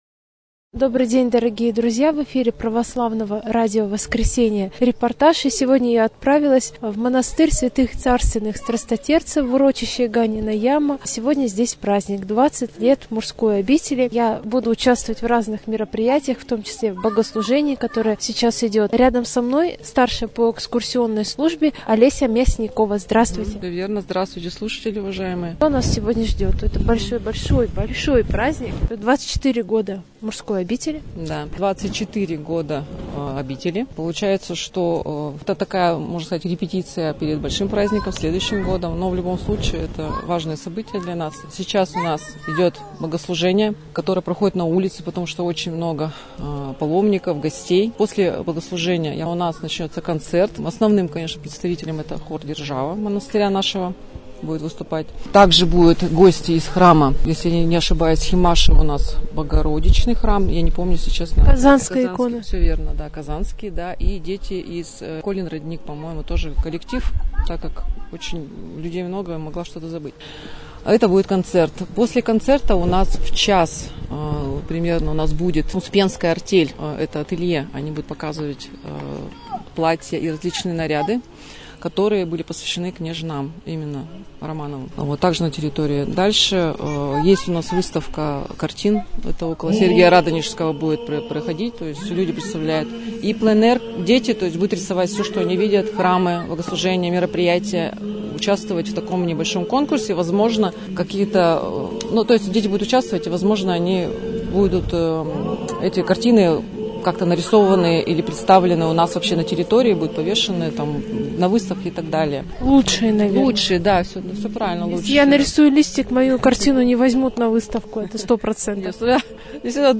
Репортаж дня | Православное радио «Воскресение»
Празднование 24-го дня рождения Царской обители
prazdnovanie_24_dnya_rozhdeniya_carskoj_obiteli.mp3